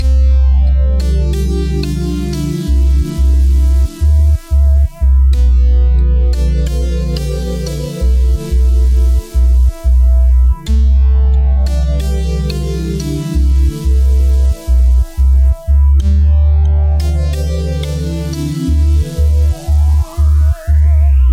Tag: 90 bpm Electronic Loops Pad Loops 3.59 MB wav Key : Unknown